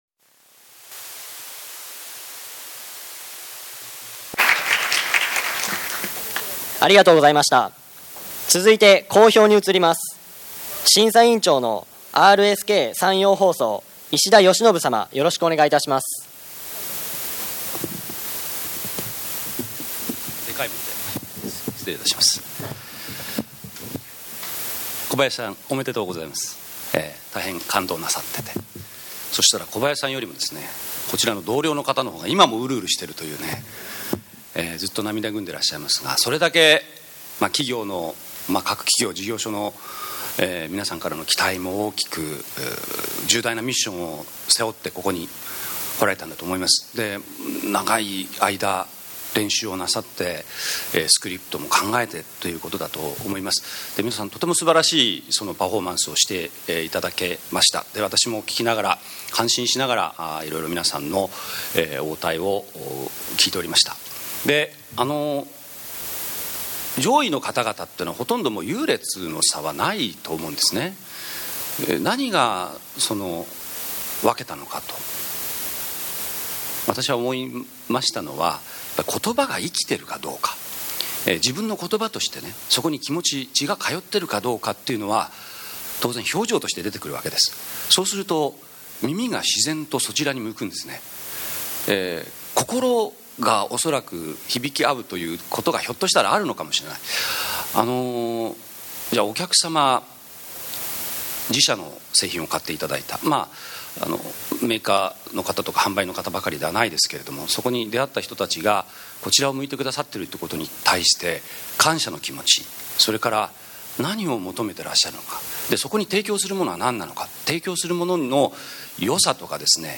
「2011年度 電話応対コンクール岡山県大会」が9月22日、岡山市北区の「メルパルク岡山」で開催され、地区大会で優秀な成績をおさめた47名の地区代表選手が、ビジネス電話応対の「心」と「技」を競い合いました。